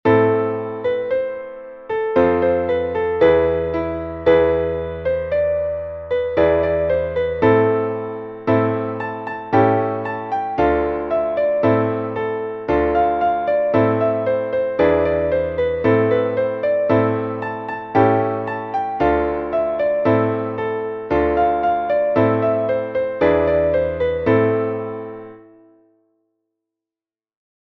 μελωδία και συγχορδίες, Amin